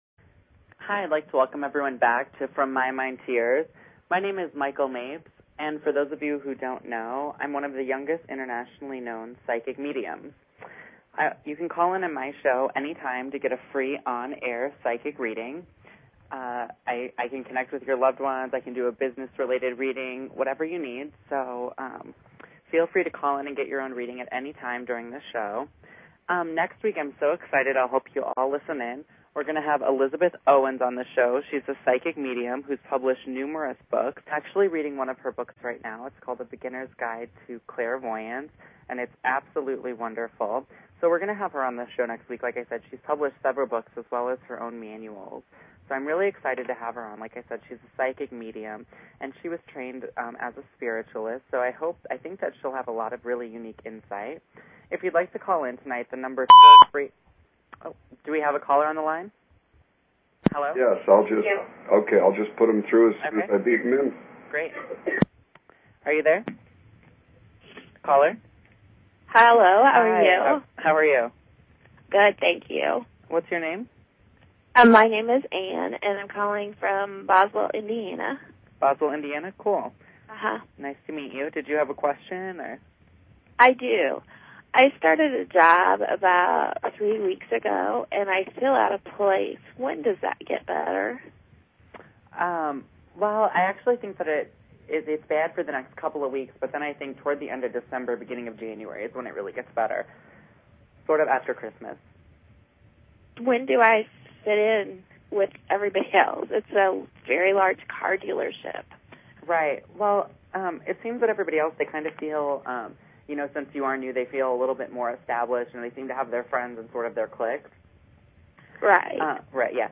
Talk Show Episode, Audio Podcast, From_My_Mind_To_Yours and Courtesy of BBS Radio on , show guests , about , categorized as